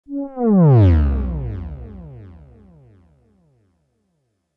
Swoop.wav